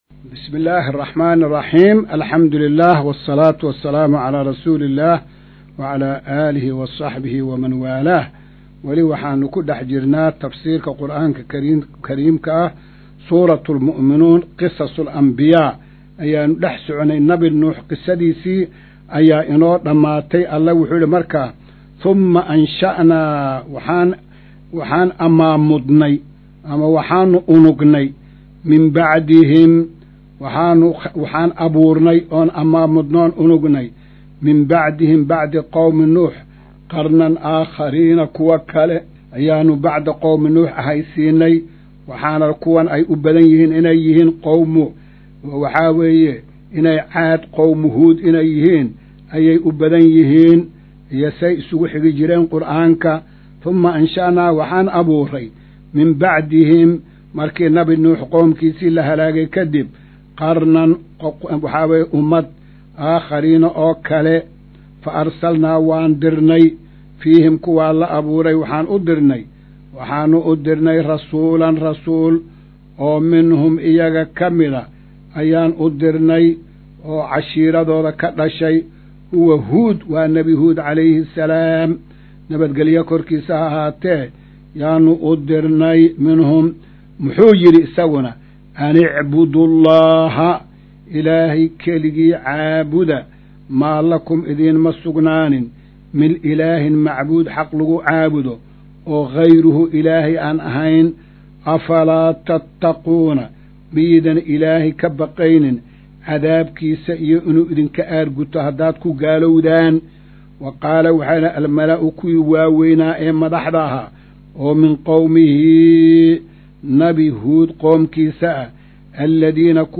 Maqal:- Casharka Tafsiirka Qur’aanka Idaacadda Himilo “Darsiga 166aad”